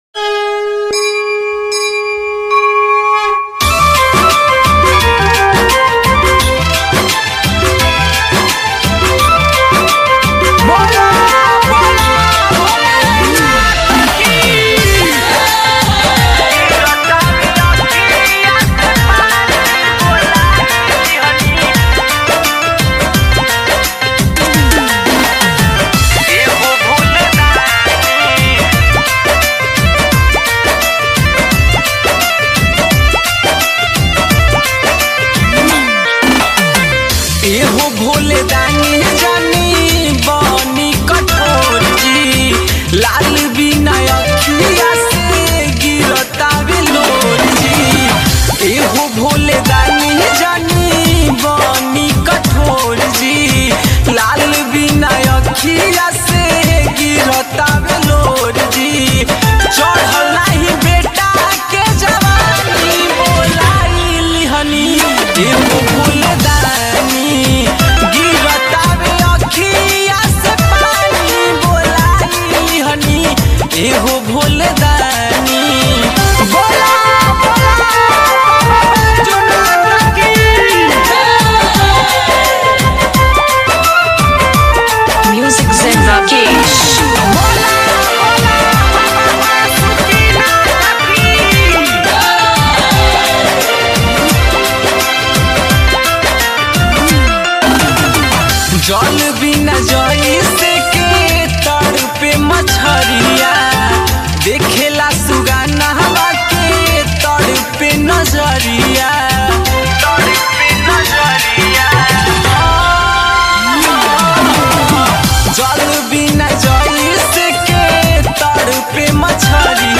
bhojpuri bol bam song